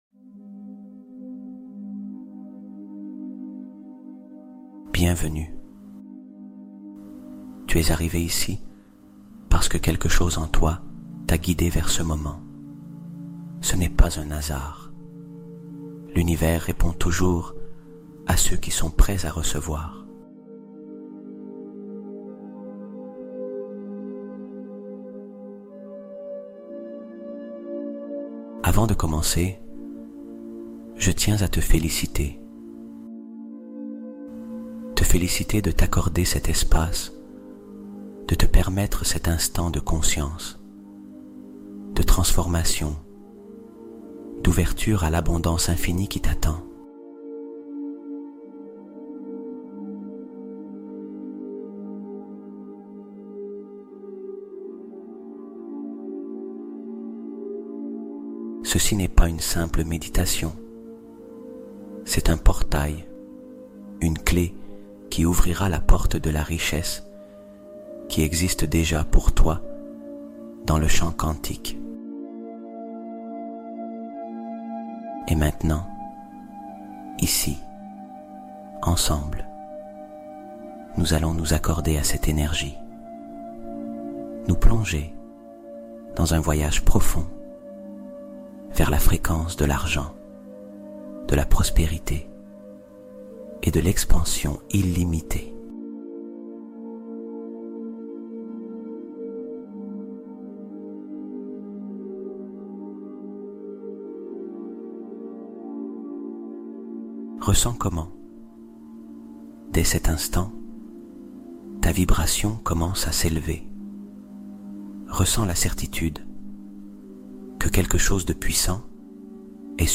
Flux de Prospérité : Hypnose inspirée pour attirer l'abondance naturelle